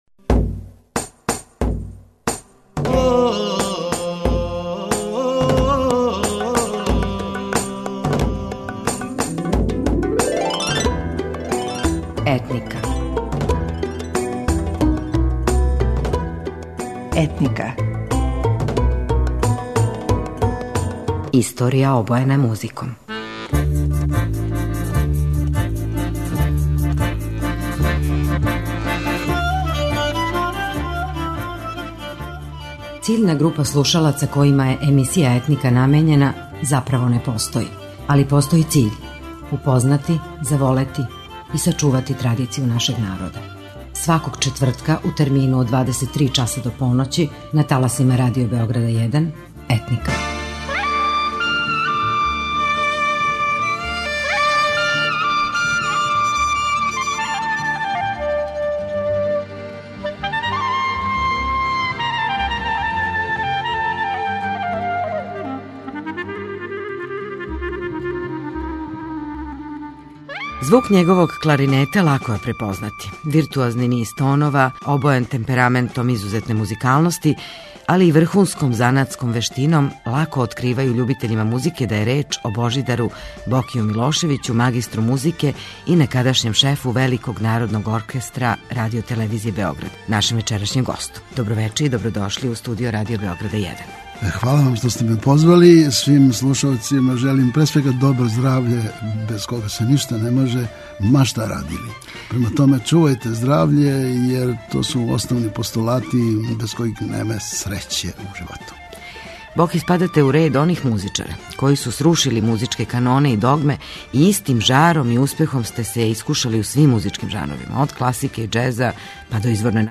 Својим искреним зналачким радом, раскошним и препознатљивим тоном и стилом свирања на кларинету, Божидар –Боки Милошевић, гост вечерашње емисије Етника, представља узор и мерило вредности многим младим генерацијама кларинетиста.
Уводи школовани начин свирања кларинета у народну музику, не одустајући ни од једног елемента академског нивоа интерпретације.